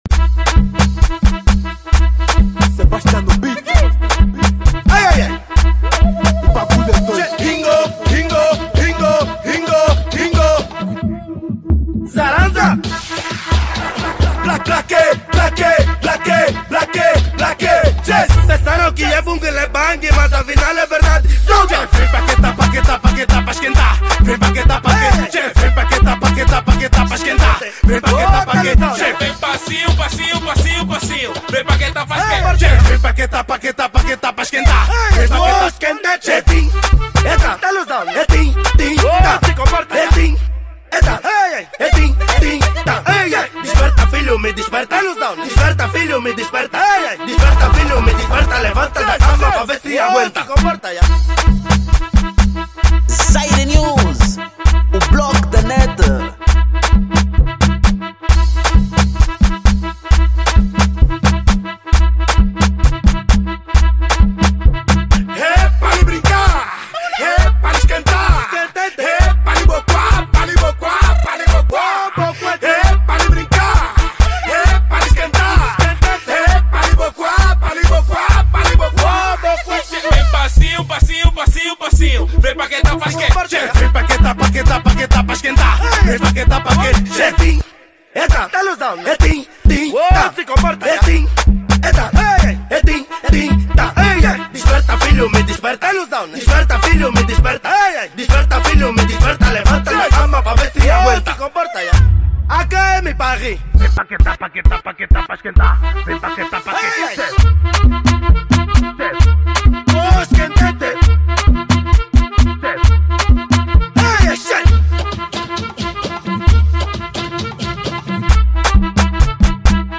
Afro House